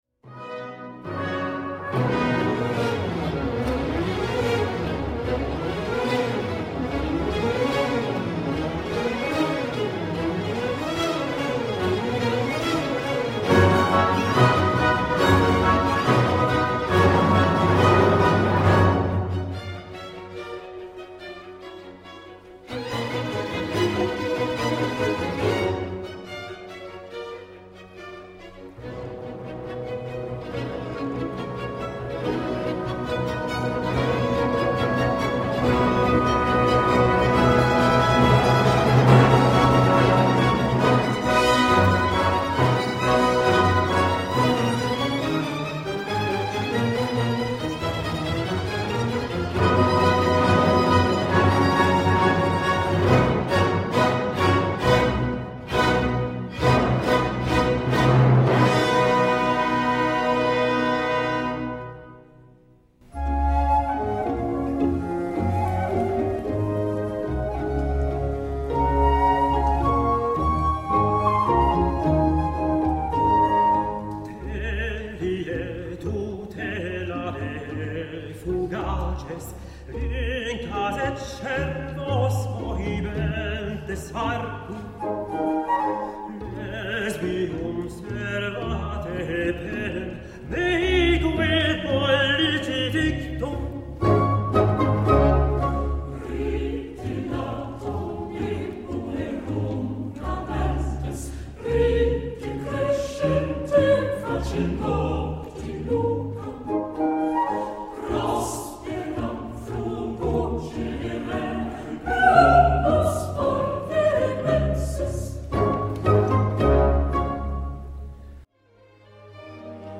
Mannheimer Hofkapelle
Vokalensemble "Ex Tempore"
Live-Mittschnitt des "Radio 4" (mp3-Datei, 15 MB)
Freitag, 7.September, 20.00 Uhr • Theater aan het vrijthof
Faszinierender Originalklang
mannheimer_hofkapelle_Philidor_Maastricht_trailer.mp3